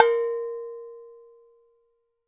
AFRCN AGOGOS